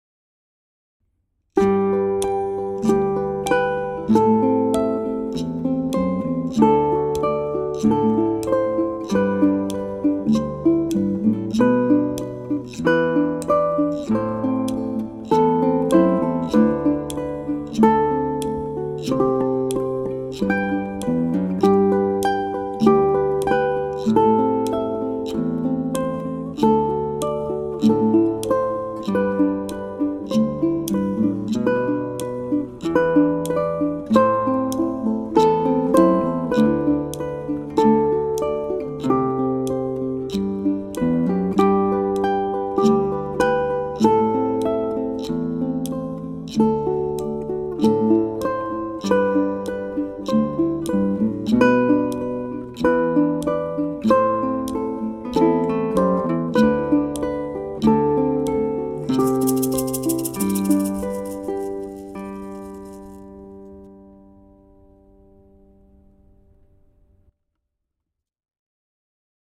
ʻukulele